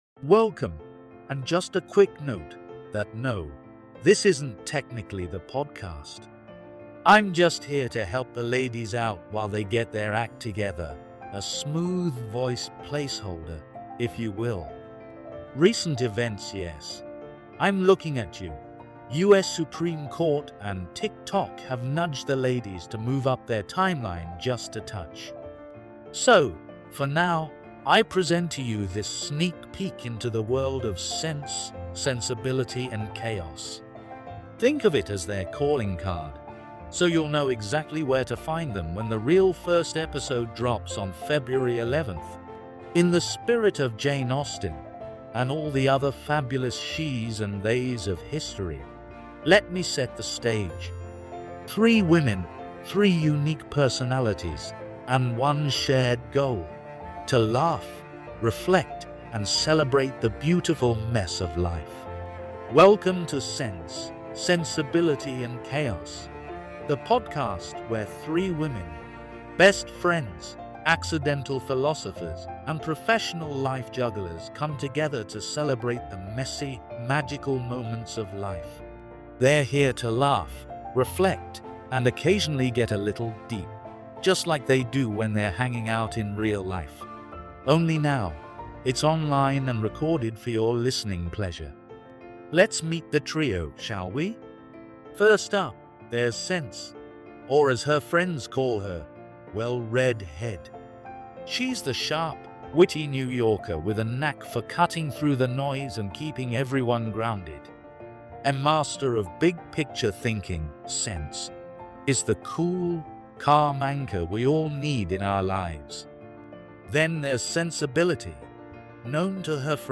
But thanks to technology and our charming (yet still unnamed) virtual MC/Butler, we’ve created this placeholder to give you a taste of what’s to come.